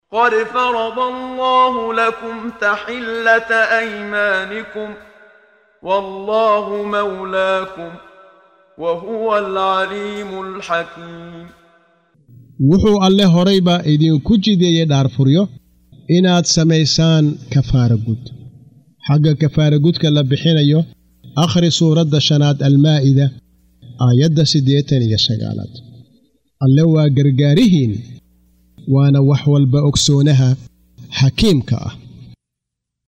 Waa Akhrin Codeed Af Soomaali ah ee Macaanida Surah At-Taxriim ( Iska xaaraantimeynta ) oo u kala Qaybsan Aayado ahaan ayna la Socoto Akhrinta Qaariga Sheekh Muxammad Siddiiq Al-Manshaawi.